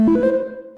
Techmino/media/effect/chiptune/spin_1.ogg at dacefb2b01bd008d29deda8780cb9177b34fc95d
spin_1.ogg